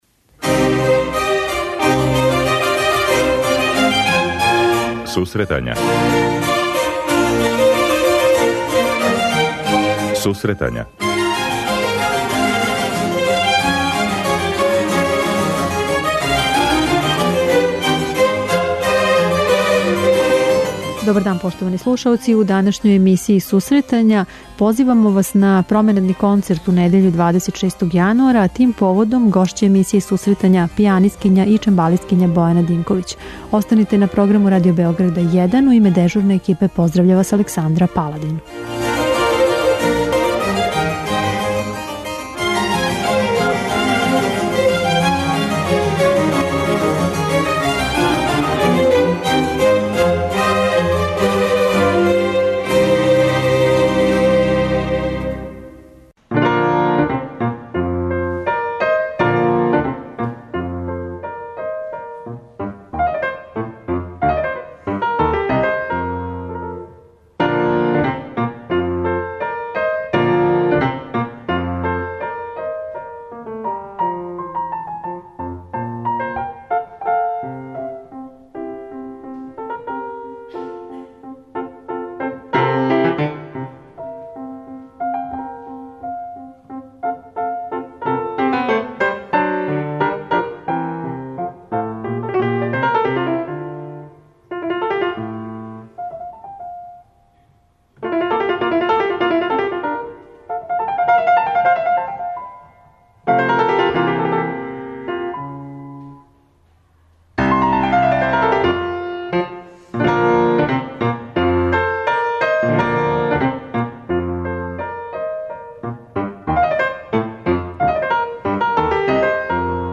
преузми : 26.03 MB Сусретања Autor: Музичка редакција Емисија за оне који воле уметничку музику.